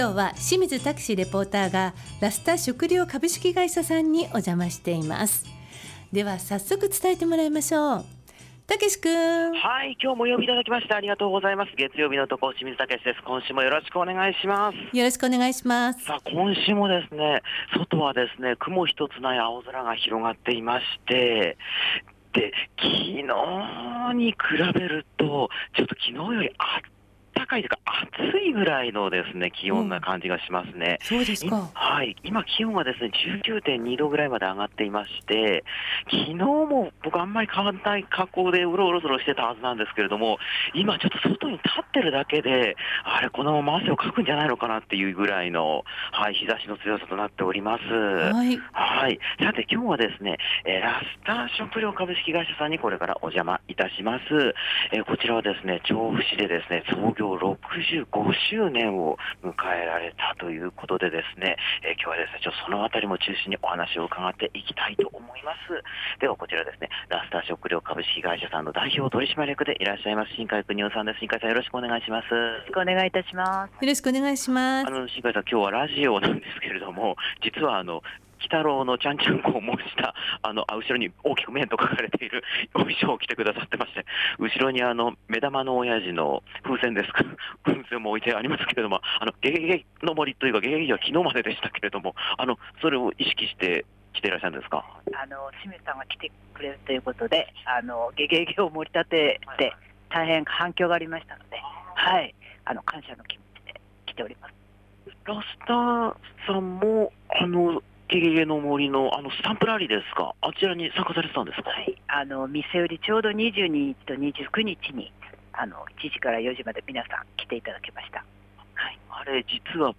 残り1ヶ月がトレンド入りしているのに暖かい空の下からお届けした、本日の街角レポートは 今年６月に創業６5年周年を迎えた調布市小島町『ラスター食糧株式会社』さんからのレポートでした。